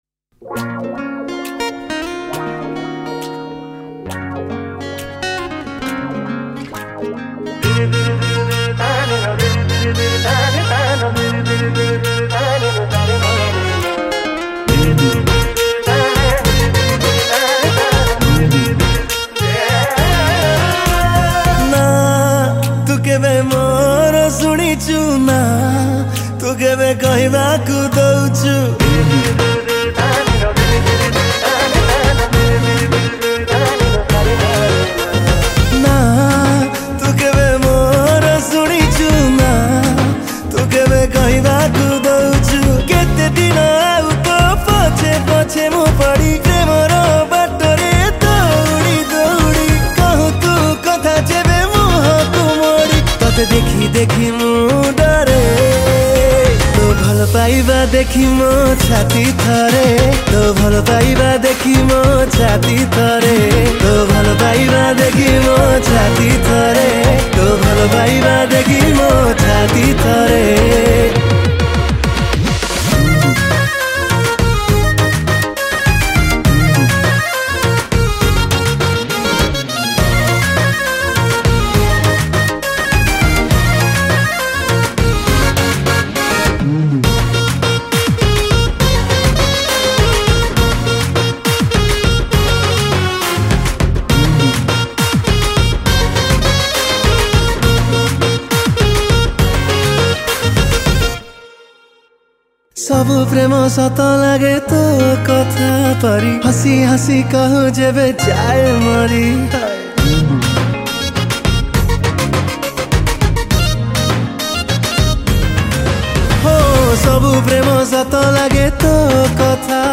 Song Type :Dance